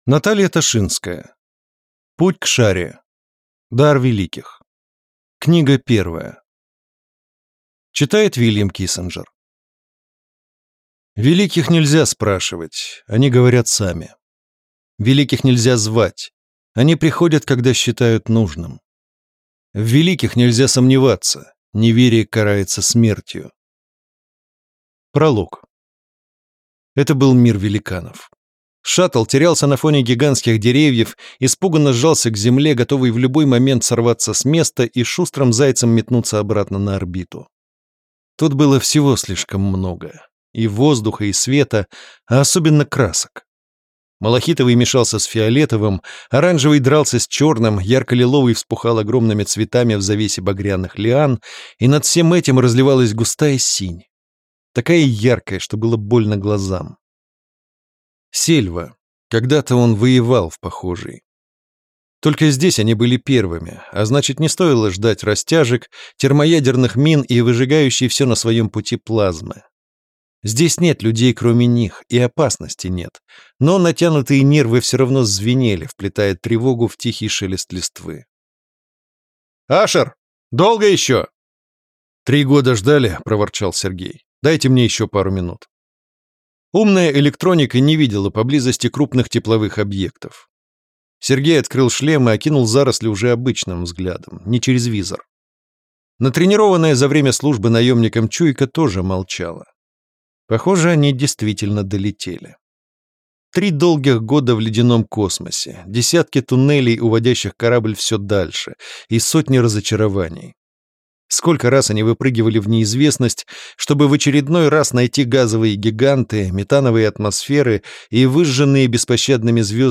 Аудиокнига Путь кшари. Дар Великих | Библиотека аудиокниг